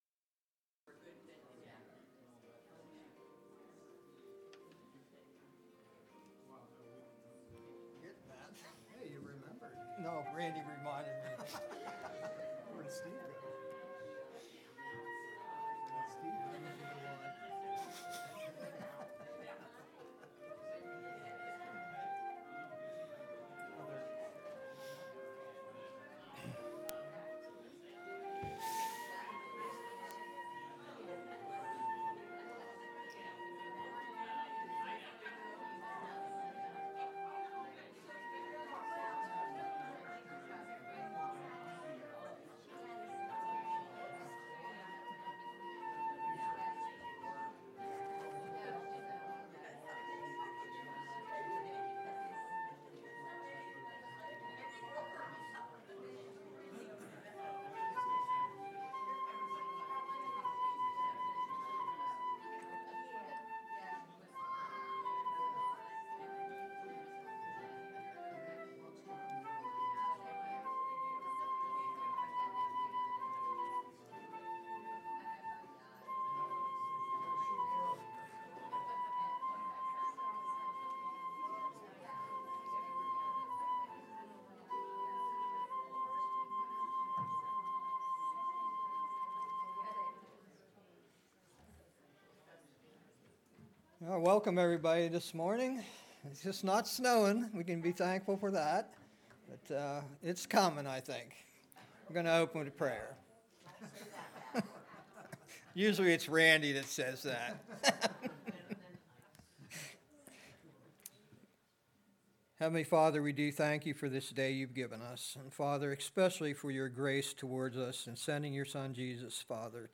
Home › Sermons › November 26, 2023